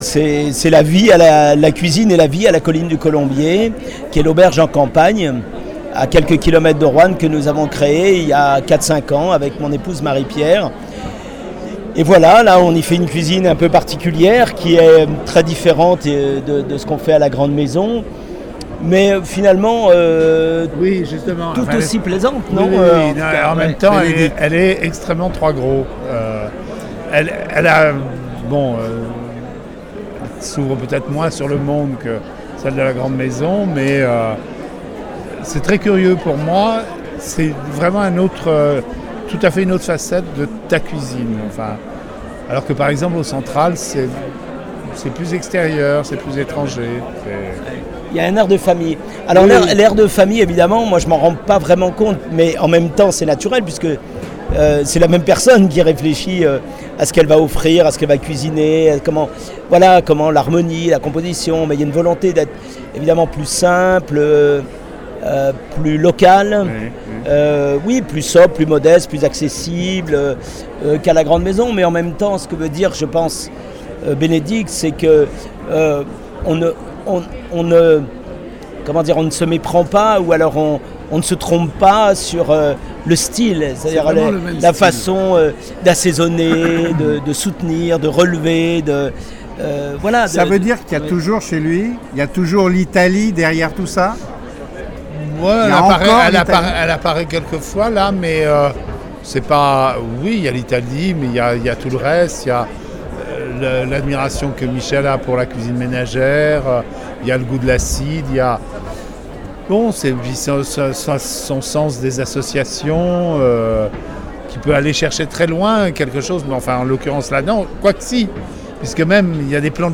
Je vous propose d’écouter ce petit entretien que j’ai eu à Périgueux avec ces deux compères si chaleureux…